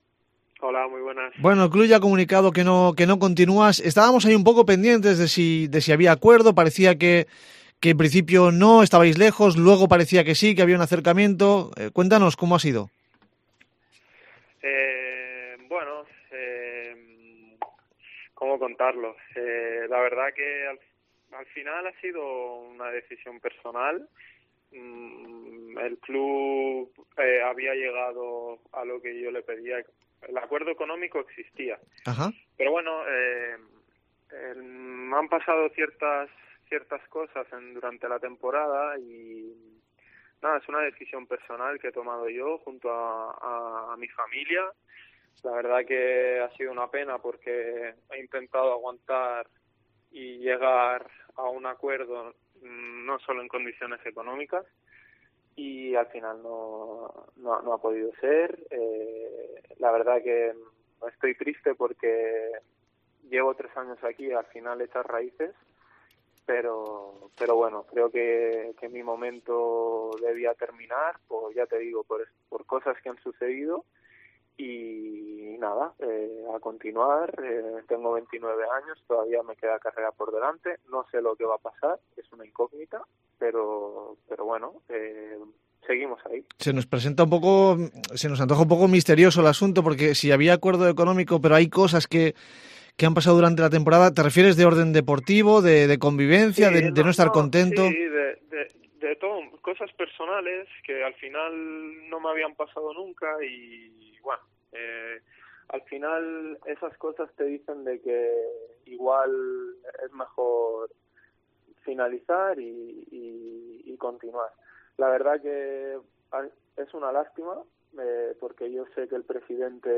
Tras los tiras y aflojas se había llegado a un acuerdo económico según dice en la entrevista pero ha decidido acabar su etapa en el club.